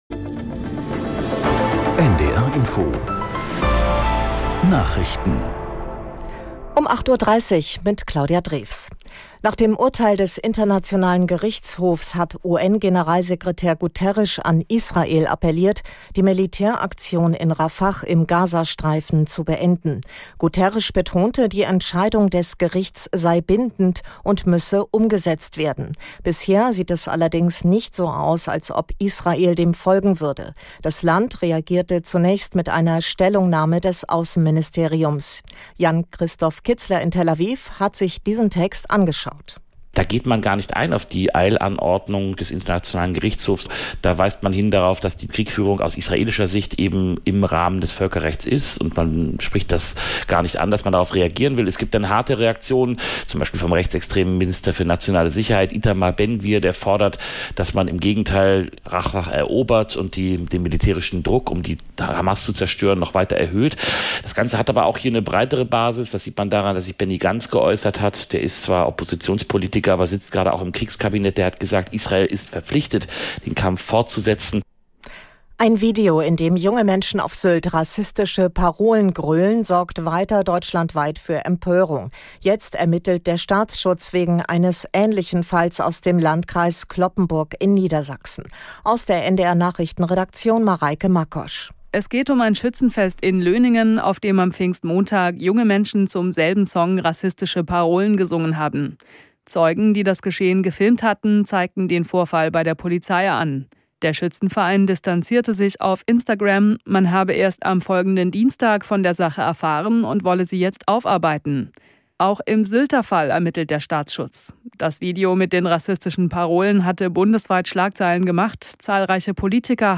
Nachrichten - 25.05.2024